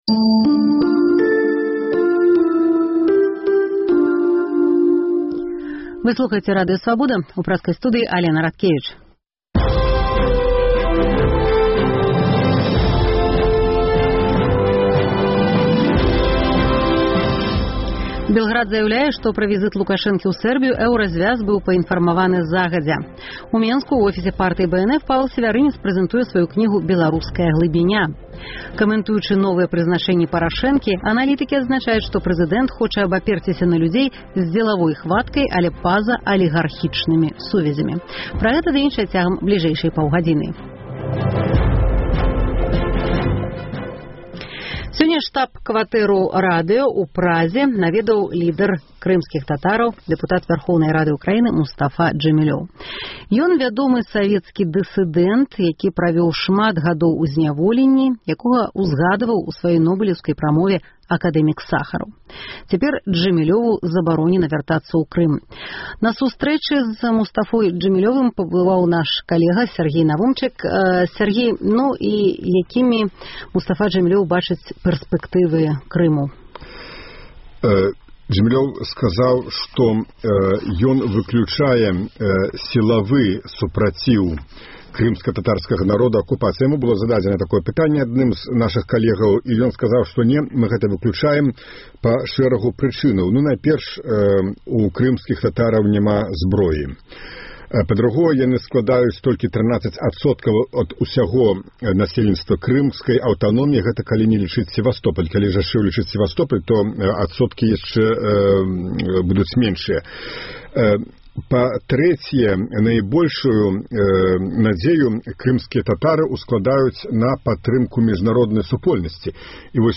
Што стаіць за гэтым запрашэньнем? Ці можна разгядаць яго як зьмену пазыцыі Эўразьвязу ў дачыненьні да Беларусі? У праграме таксама інтэрвію